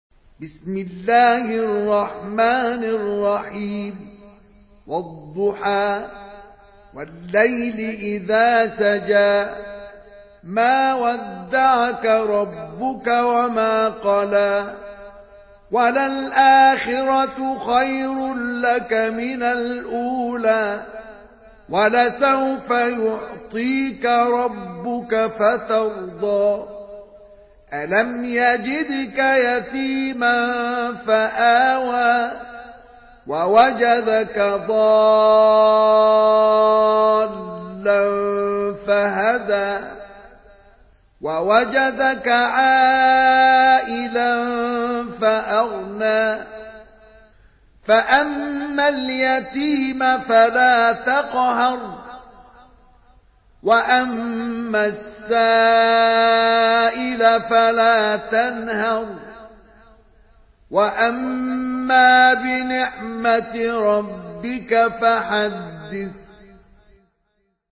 Surah Duha MP3 by Mustafa Ismail in Hafs An Asim narration.
Murattal Hafs An Asim